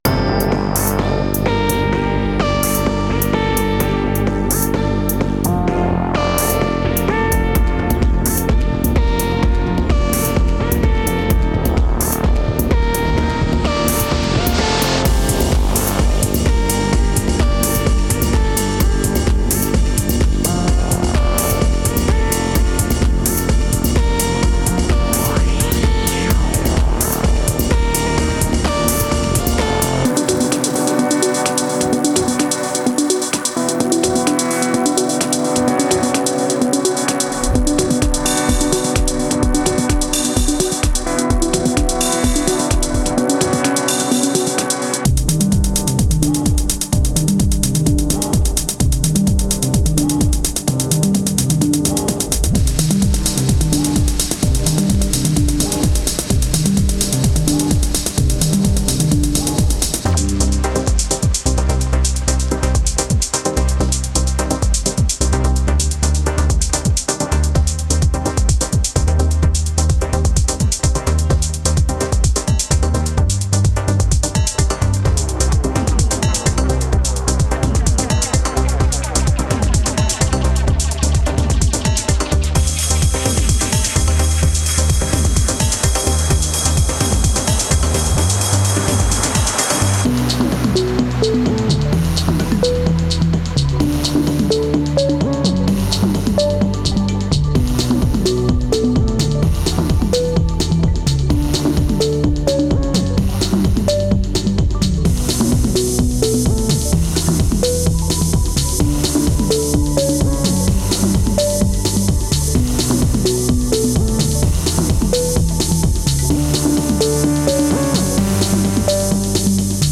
Black Corporation Deckard’s Dream & Kijimi
デモサウンドはコチラ↓
Genre:Melodic Techno
100 Loops recorded at 128bpm